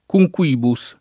cumquibus [ ku j k U& bu S ]